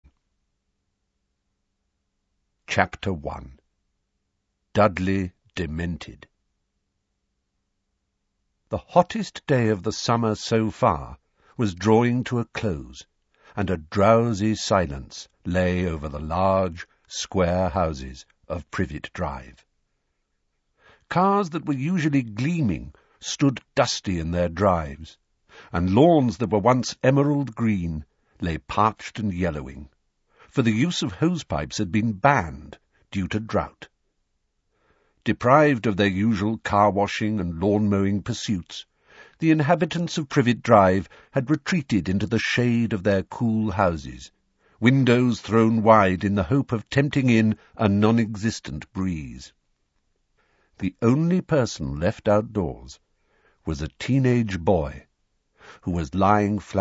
کتاب صوتی انگلیسی هری پاتر و محفل ققنوس + (فایل PDF) | مرجع دانلود زبان
بخشی از کتاب انگلیسی هری پاتر و محفل ققنوس: